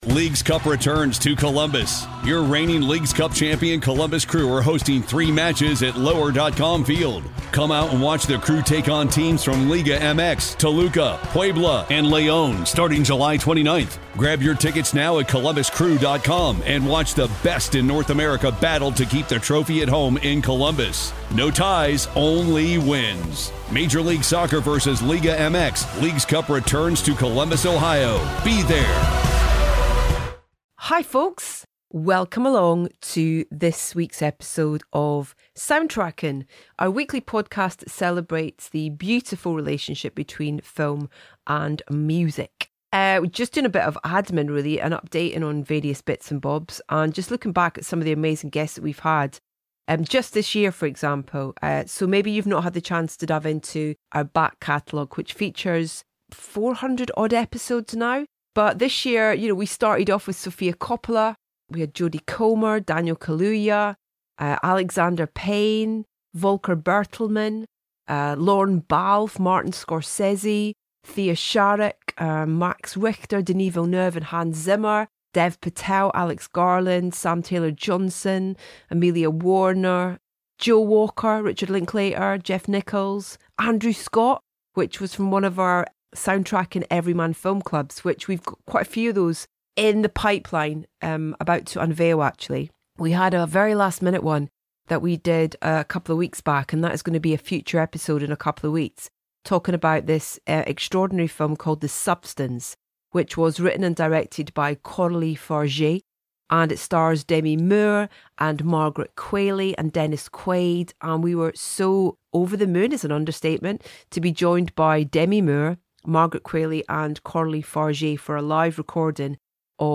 Our latest guests on Soundtracking are director Iain Softley and musician Don Was, who joined Edith earlier this year to discuss the 30th Anniversary of Backbeat.